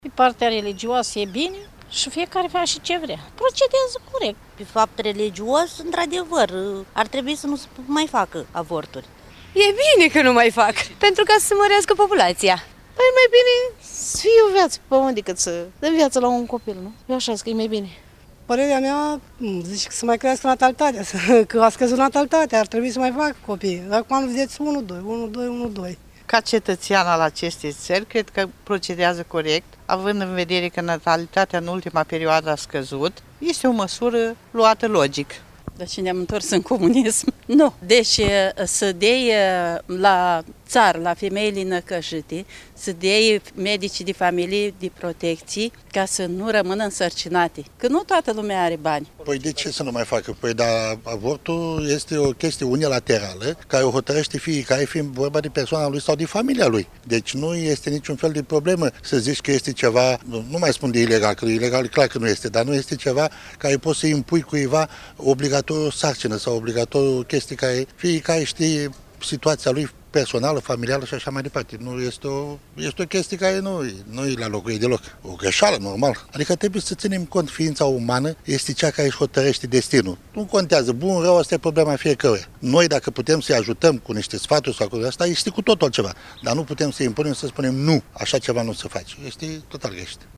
Vasluienii au păreri împărţite în privința acestui subiect:
27-sept-ora-14-vox-vasluieni.mp3